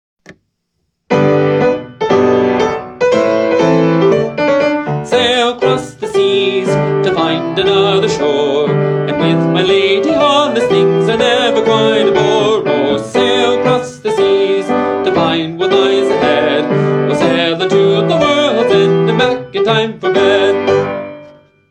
Easy Piano and Voice